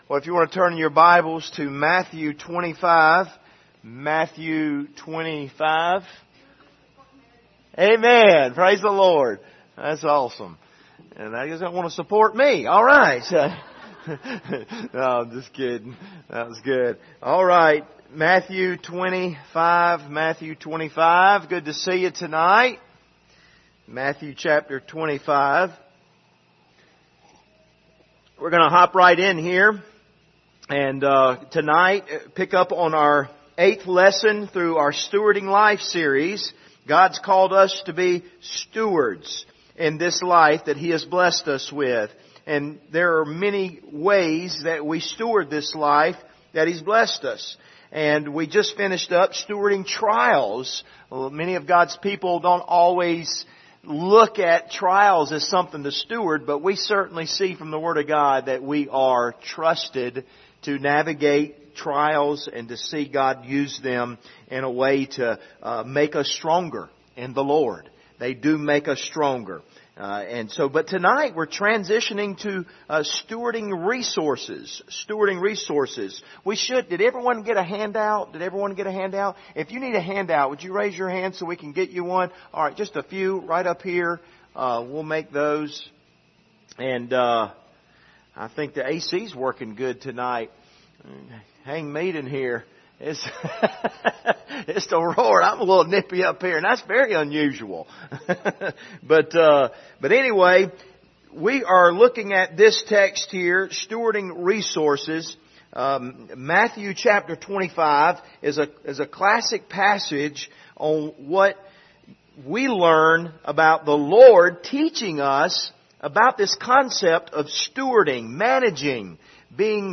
Passage: Matthew 25:14-30 Service Type: Wednesday Evening